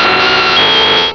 pokeemerald / sound / direct_sound_samples / cries / nidoking.aif
-Replaced the Gen. 1 to 3 cries with BW2 rips.